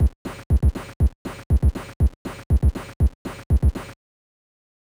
Because digital audio and overall computing processing was at early stages at this point, the digital samples that the 2A03 could process had to be heavily compressed to 7-bit resolution. The audio examples below compare a drum beat uncompressed with the orignal samples against a compressed one passed through a 2A03 emulator.
Beat_comp.wav